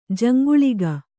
♪ jaŋguḷiga